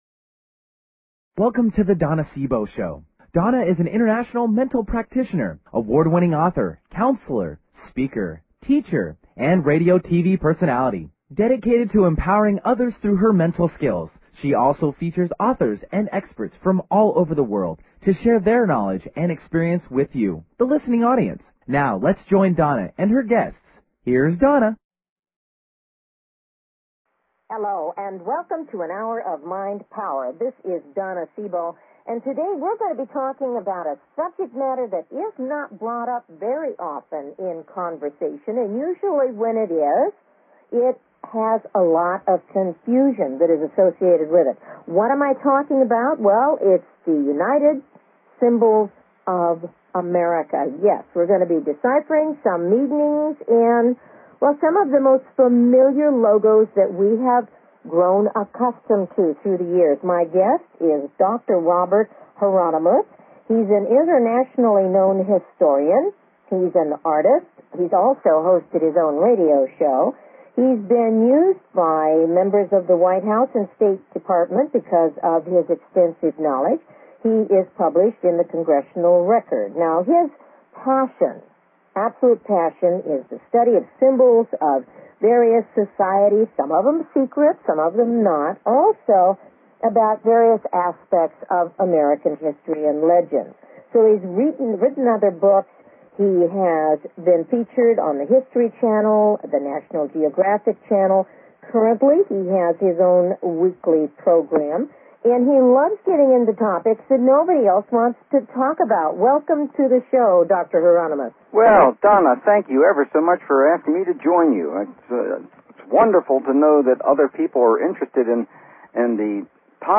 Talk Show Episode, Audio Podcast
Her interviews embody a golden voice that shines with passion, purpose, sincerity and humor.